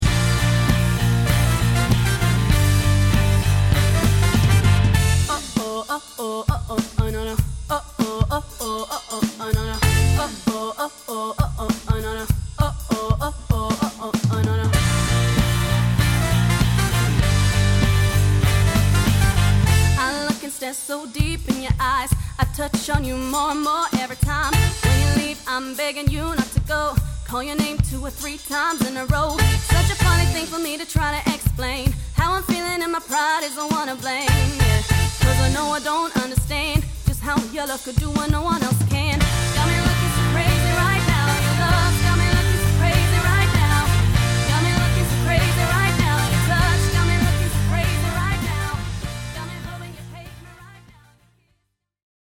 This amazing band specialises in QUALITY live entertainment!